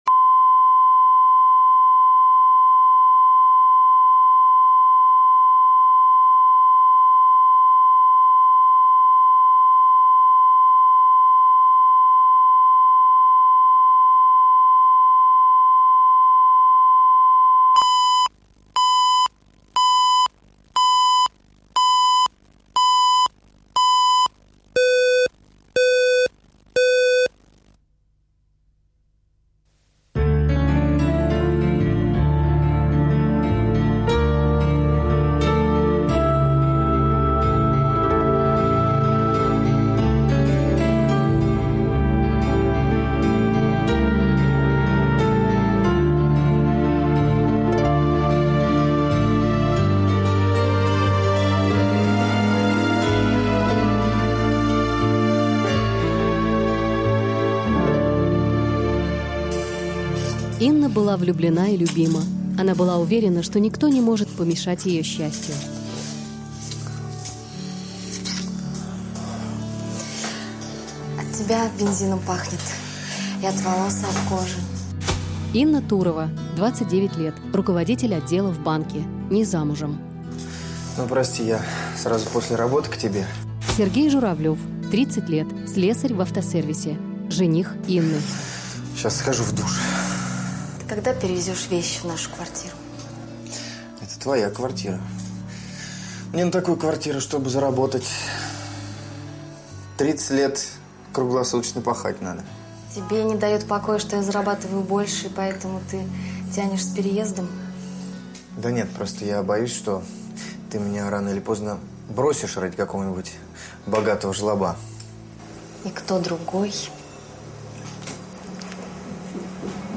Аудиокнига Ставка на сильнейшего | Библиотека аудиокниг